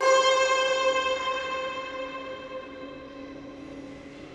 Sitar One Shot.wav